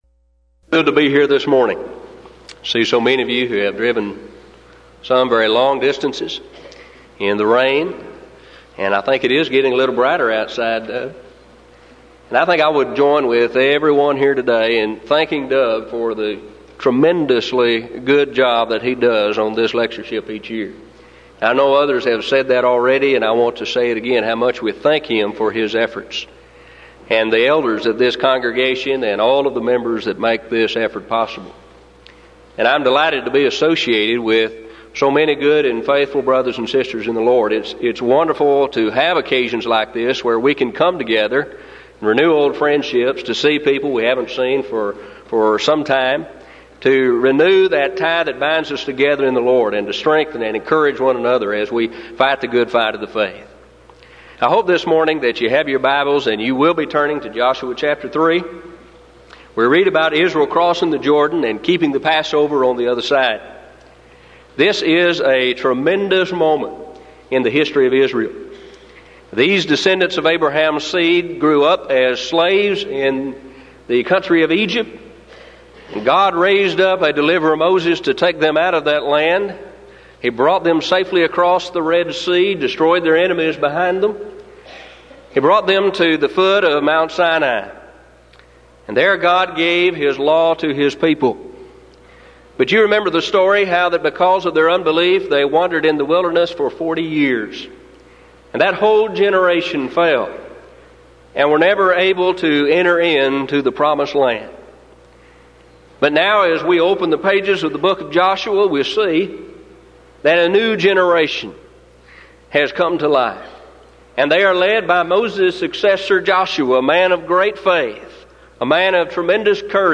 Event: 1994 Denton Lectures Theme/Title: Studies In Joshua, Judges And Ruth
lecture